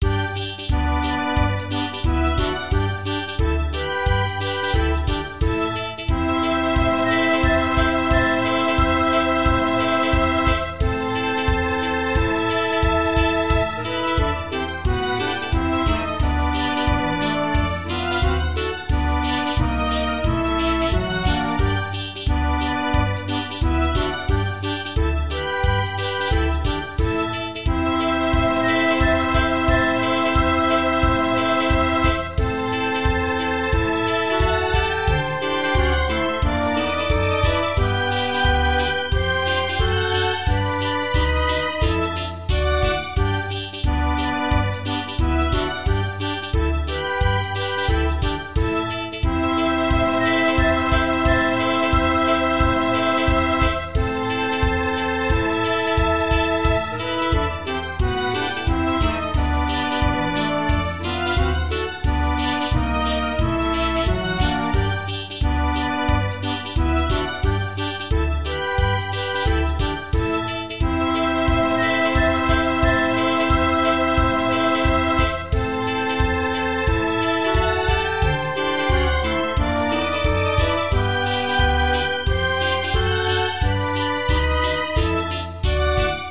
ストリングスとクラリネットが緩やかに流れるようなメロディーを歌います。
ループ